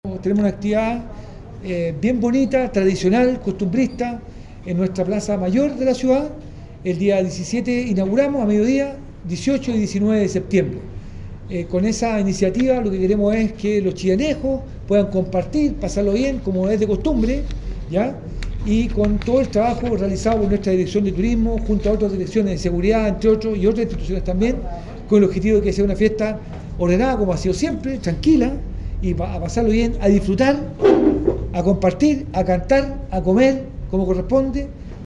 El alcalde Camilo Benavente destacó que el encuentro busca reunir a la comunidad en un ambiente seguro y familiar.